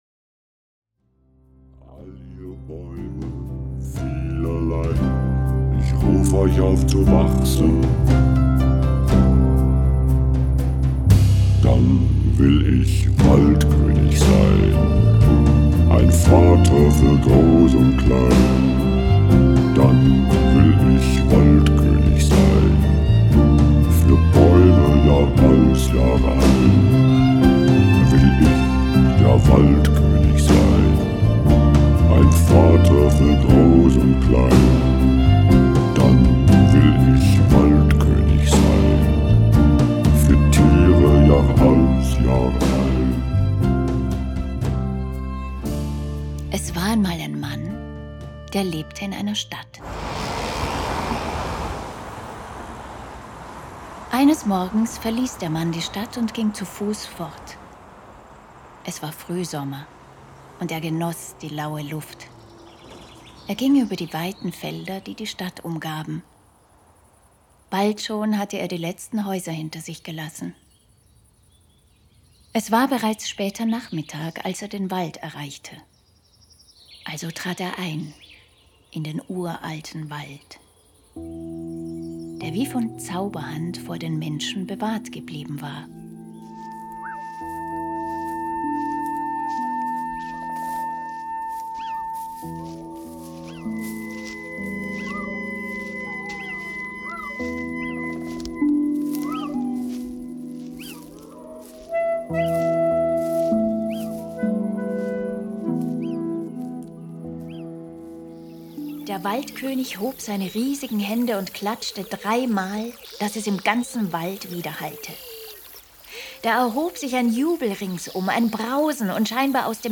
Rav-Drum, Schlagzeug, Perkussion, Herzharfe, Gesang
Piano, Keyboards
Klarinetten
Bass
Nymphen-Gesang, Doppelflöte
Cello
Violine